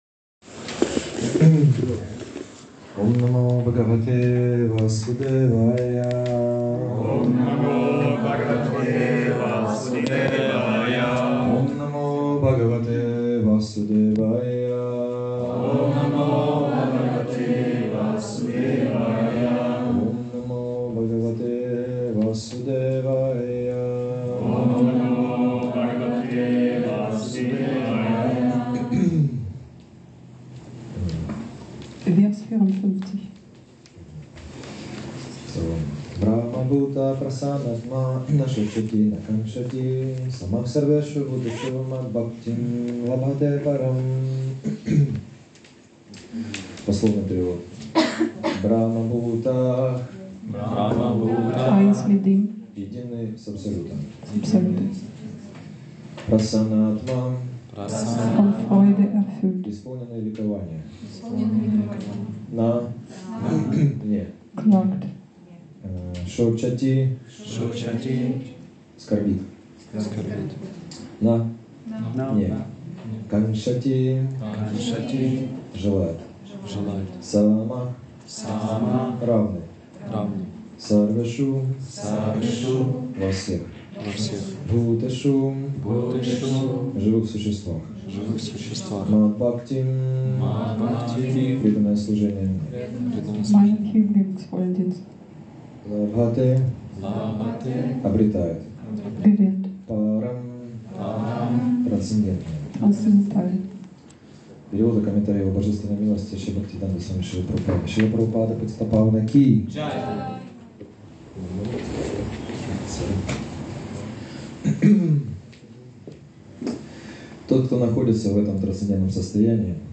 Sonntagsfest 12. Mai 2024 im Bhakti Yoga Zentrum Hamburg